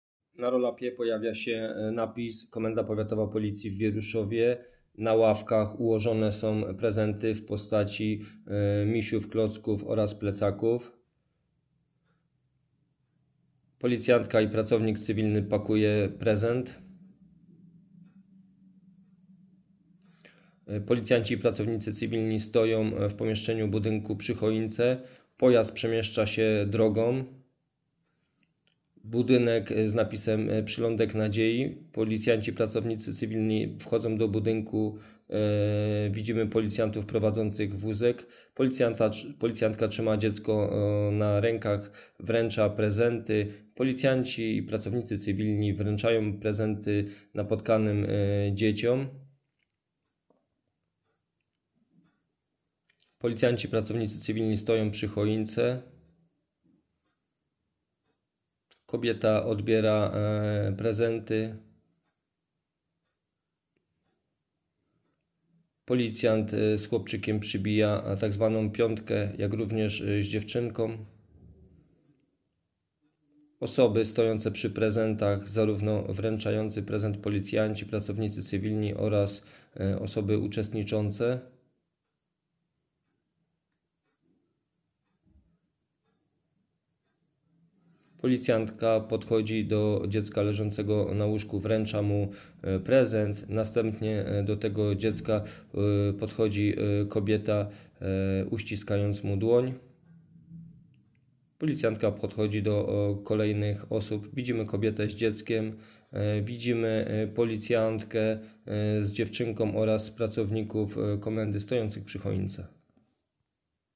Nagranie audio audiodeskrypcja_do_filmu_Policjanci_z_prezentami.m4a